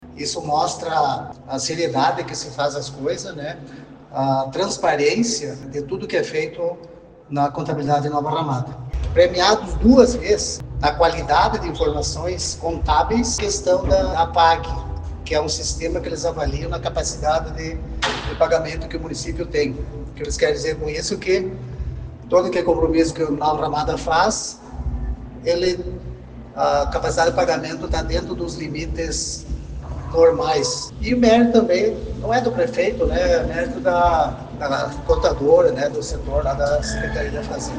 Para o prefeito, Alzevir de Marchi, a premiação demonstra a transparência no trabalho com os recursos públicos. (abaixo, áudio de Alzevir).